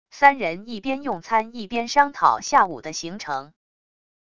三人一边用餐一边商讨下午的行程wav音频